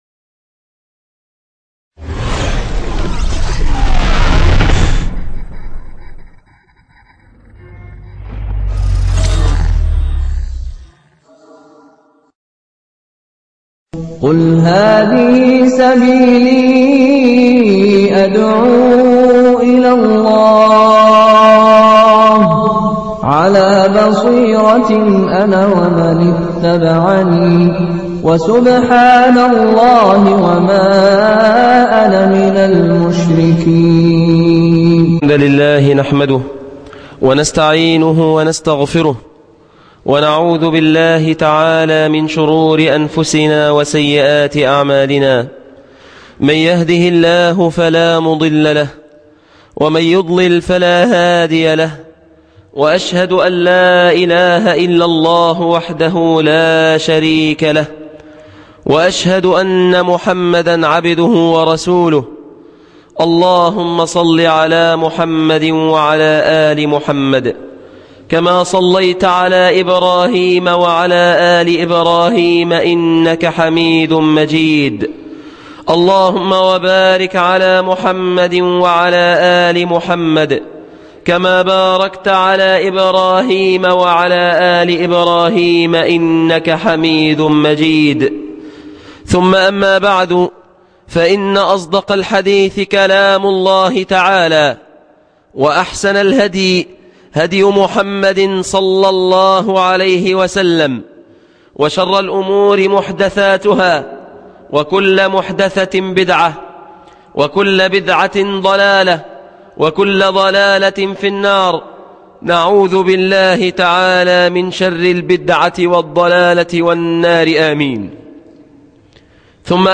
وقفات في زمن الفتن و الأزمات - خطبة بمسجد المدينة الجامعية جامعة حلوان 15 صفر 1434 هـ